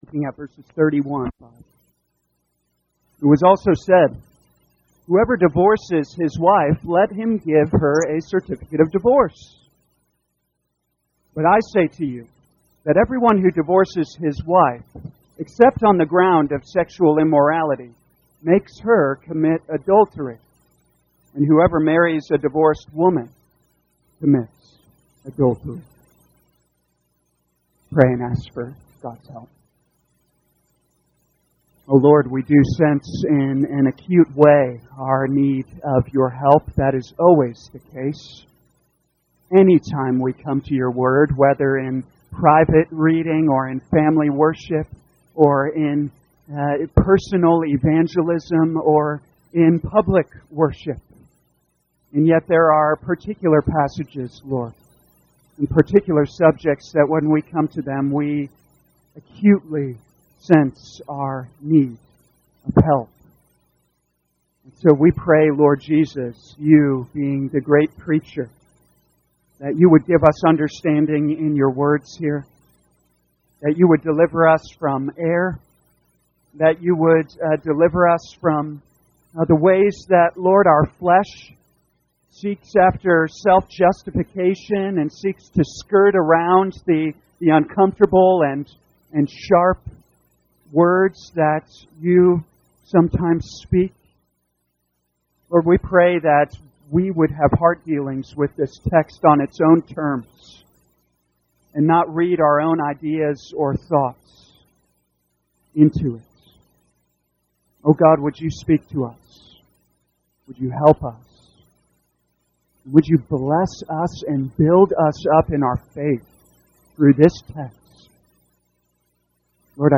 2023 Matthew Evening Service Download